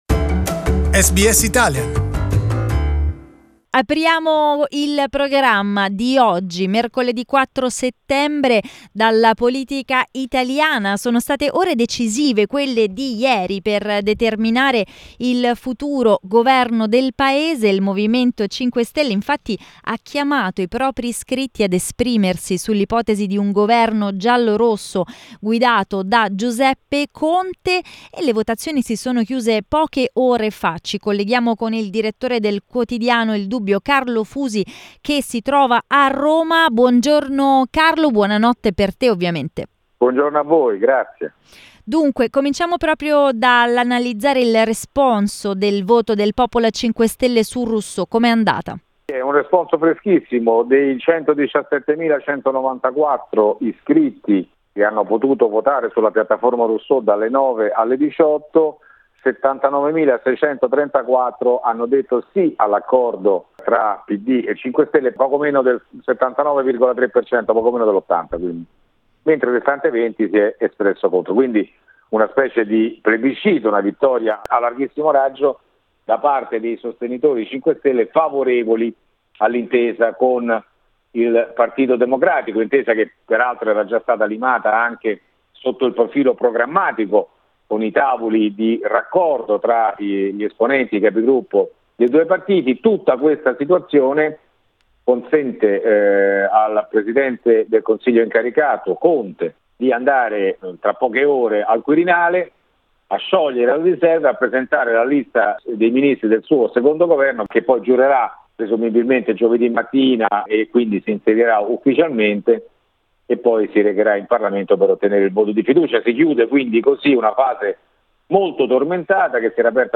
Italian journalist